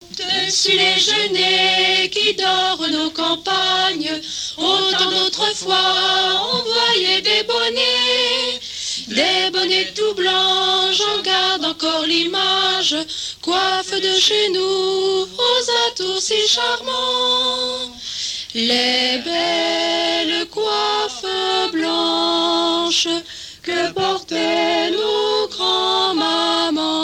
Saint-Pierre-du-Chemin
Genre strophique
Pièce musicale inédite